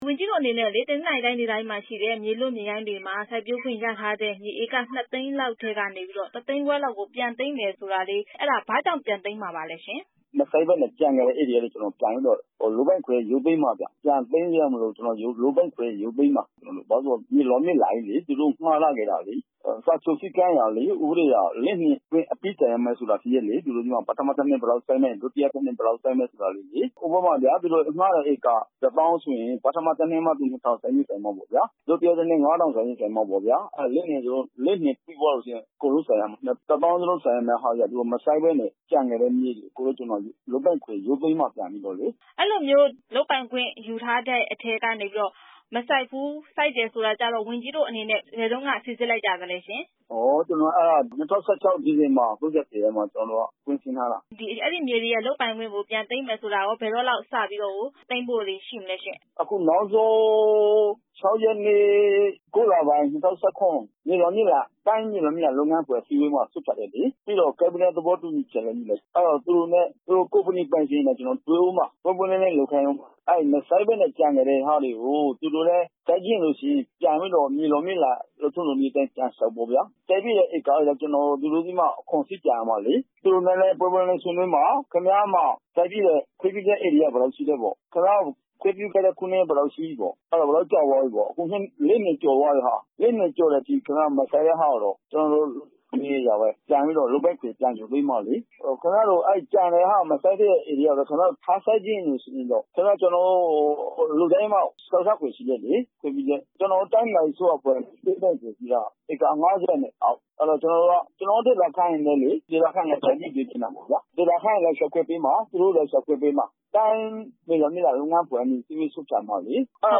သတ်မှတ်ကာလအတွင်း မစိုက်ပျိုးတဲ့ မြေများ ပြန်သိမ်းမယ့်အပေါ် မေးမြန်းချက်